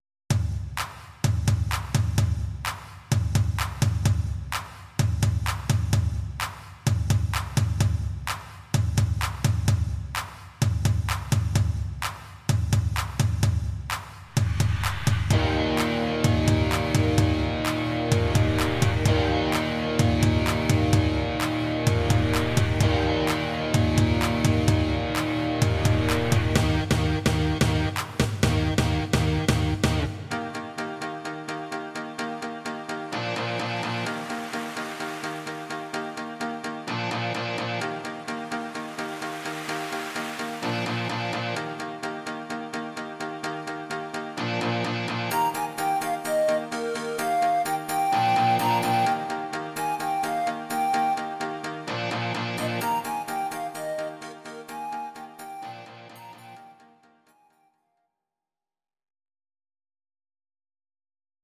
Audio Recordings based on Midi-files
Pop, 2010s